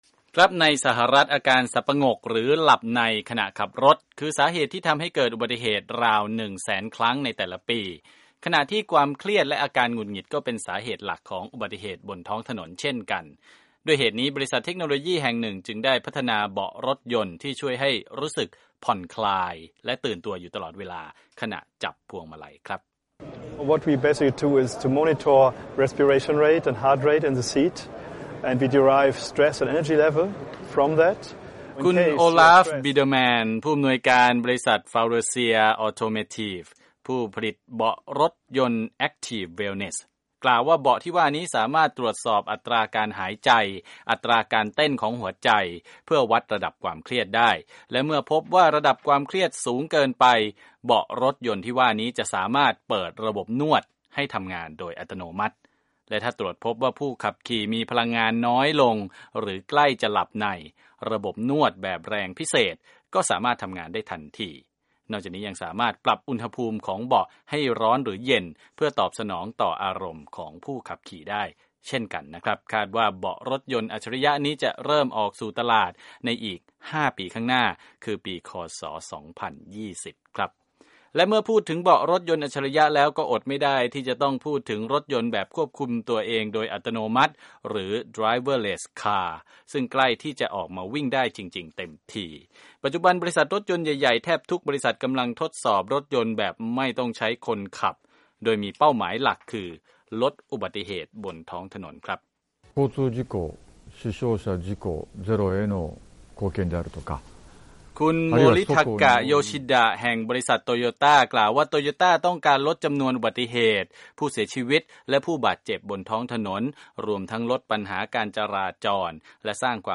ข่าวเทคโนโลยี 13 ต.ค 2558